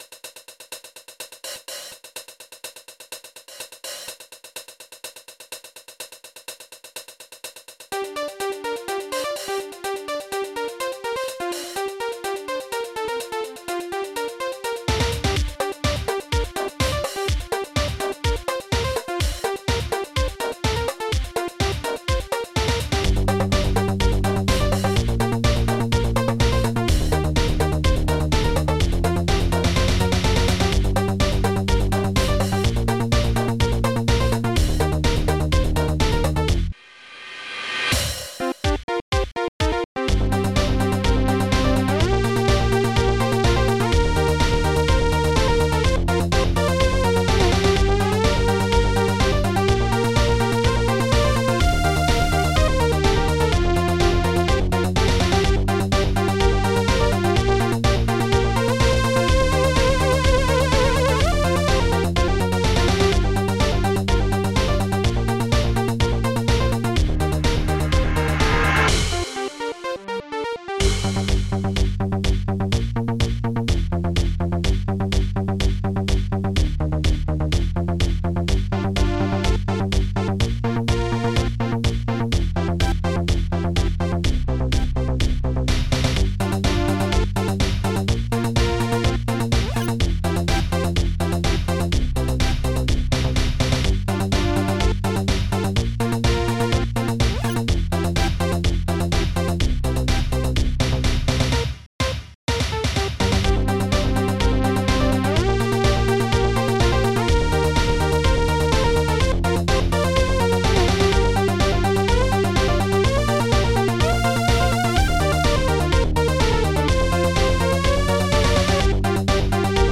ST-07:conga3
ST-07:reversecymbal
ST-07:hihatcl5
ST-03:powerbassdrum1
ST-04:synthpiano